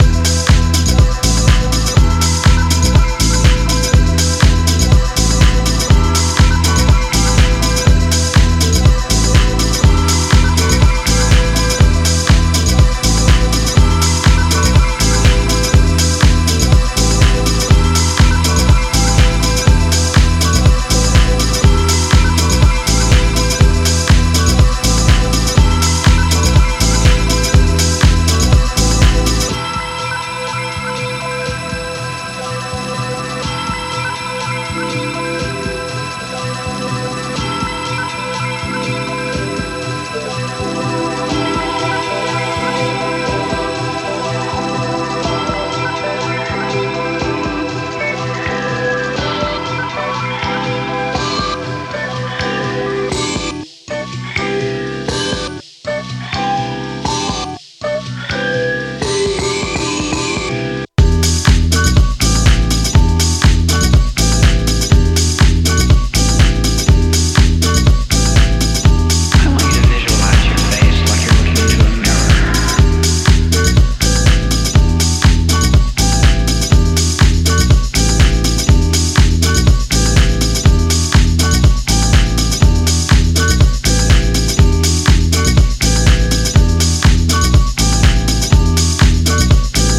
音質も良好。
ジャンル(スタイル) DEEP HOUSE / NU DISCO